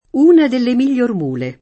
migliore [mil’l’1re] agg.